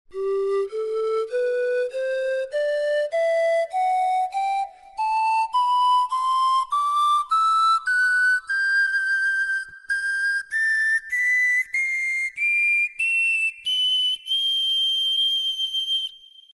Панфлейта UU-22
Панфлейта UU-22 Тональность: G
Полный альт (соль первой – соль четвертой октавы).
Материал: пластик ABS.